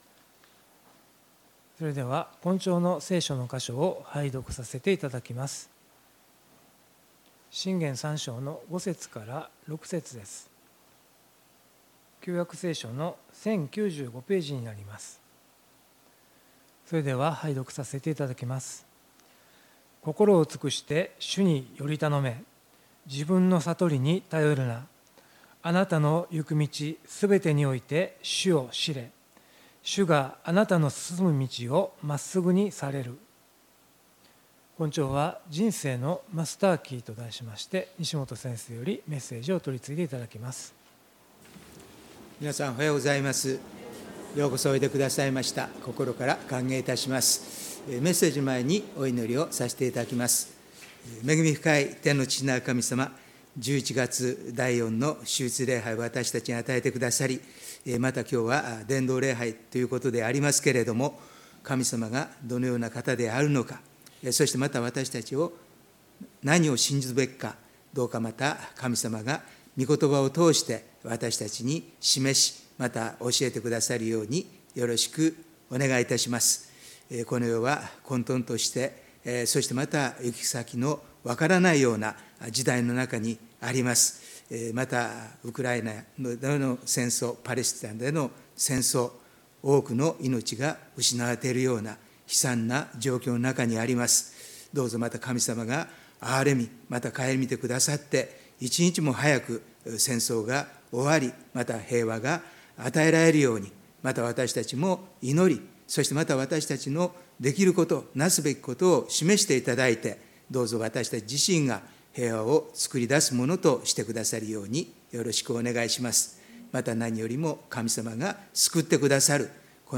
礼拝メッセージ「人生のマスターキー」│日本イエス・キリスト教団 柏 原 教 会